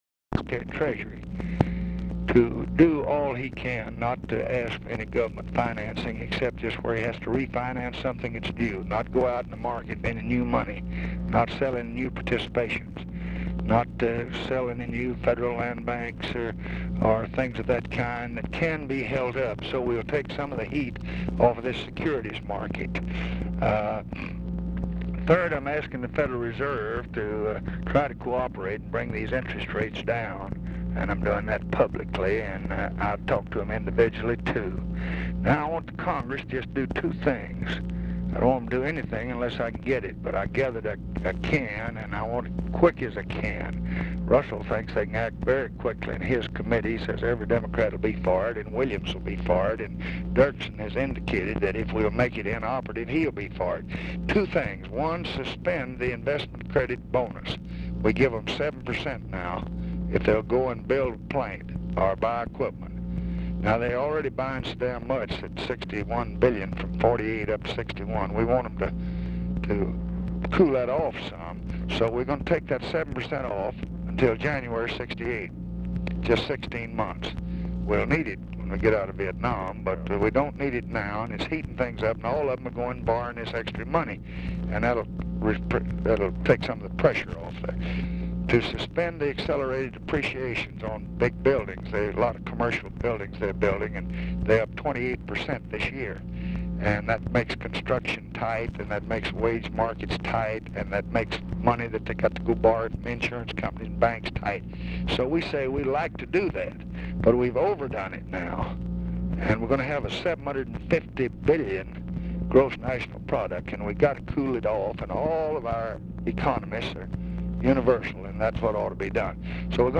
RECORDING STARTS AFTER CONVERSATION HAS BEGUN
Format Dictation belt
Specific Item Type Telephone conversation Subject Business Congressional Relations Economics Federal Budget Legislation Procurement And Disposal Taxes Vietnam